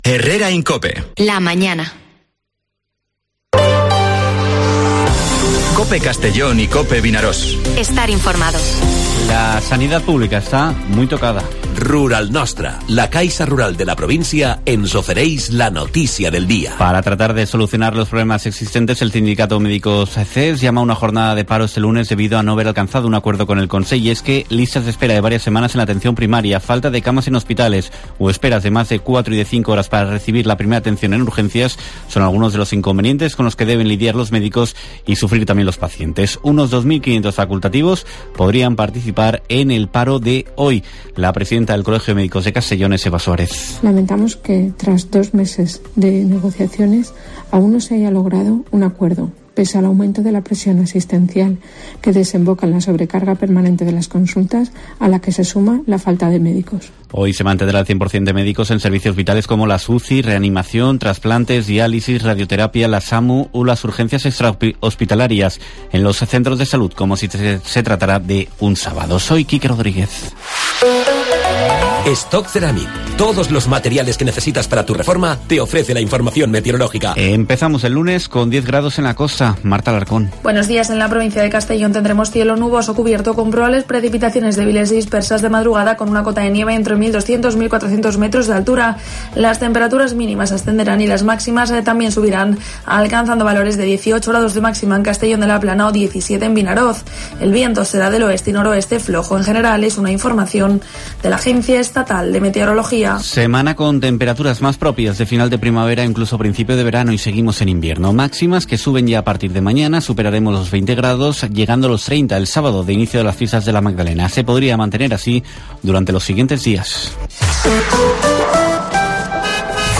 Informativo Herrera en COPE en la provincia de Castellón (06/03/2023)